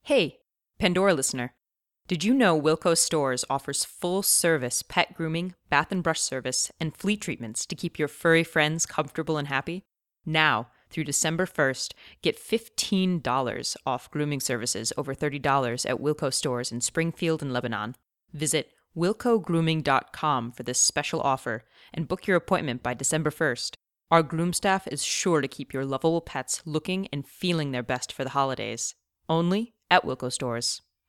Mezzo-soprano, character actor, audiobooks, games, commercials
Sprechprobe: Werbung (Muttersprache):